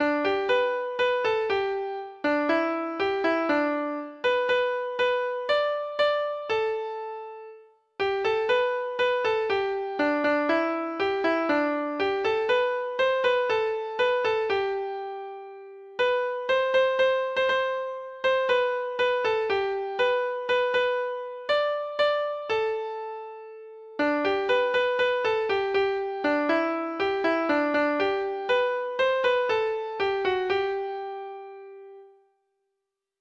Treble Clef Instrument version
Folk Songs